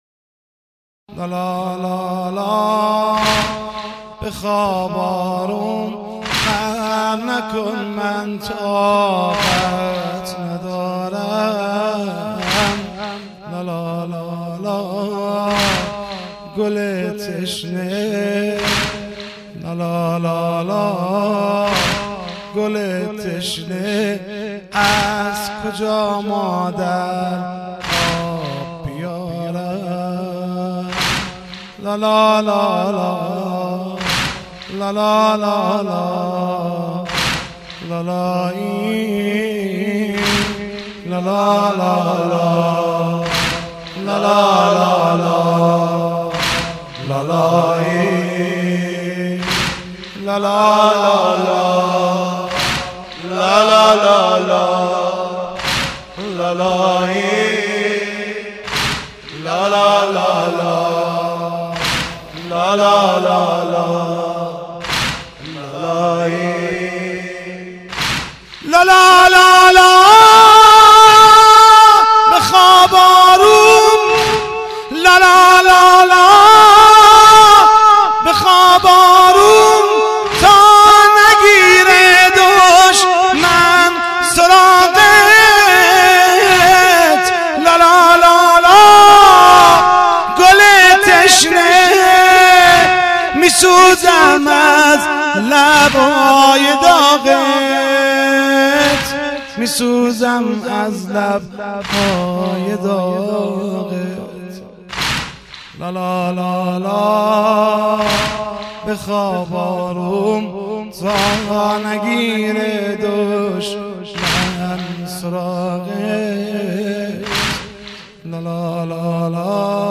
شامل هفت فایل صوتی مداحی شیرازی